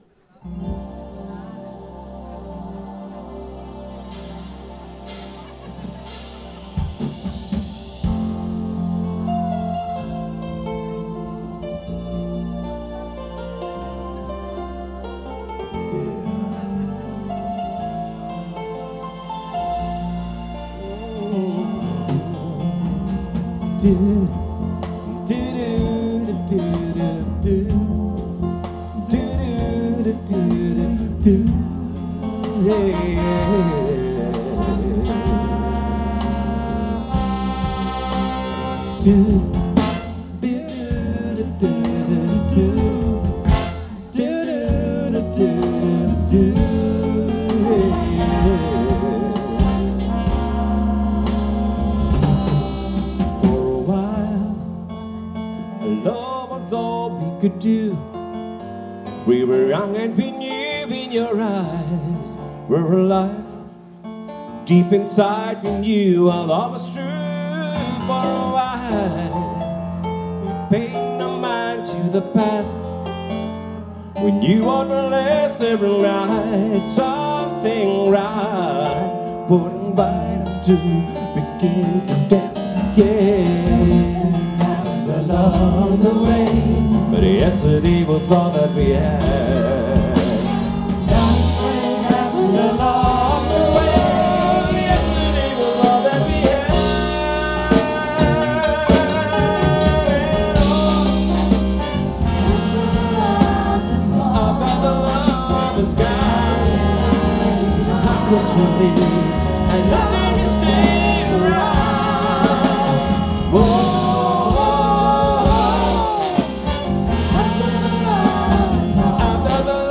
· Genre (Stil): Spoul
· Kanal-Modus: mono · Kommentar